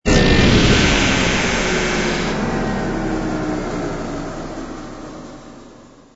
engine_li_cruise_stop.wav